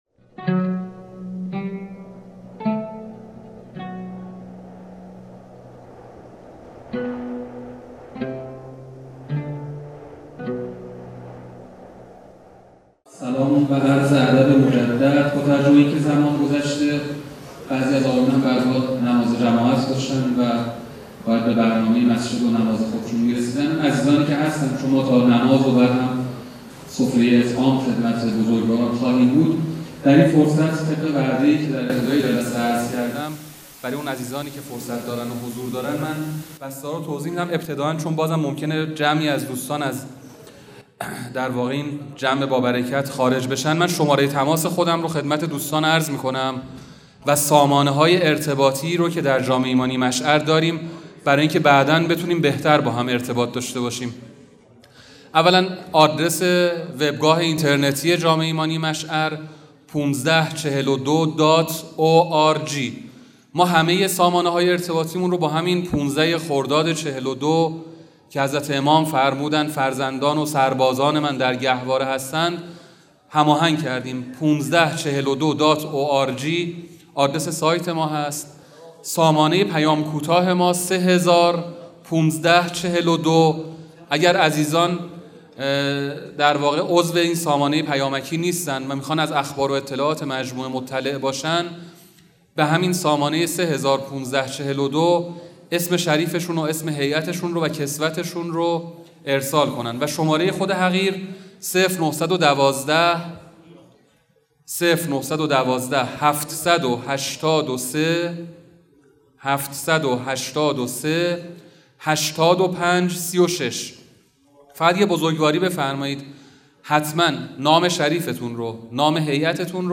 سخنرانی
نخستین نشست تخصصی فعالان عرصه هیأت